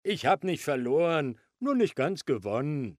The amusing winter games feature the original German voices of Sid, Manny, Diego, and Ellie!